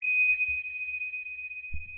sonarPingSuitFar3_Directional.ogg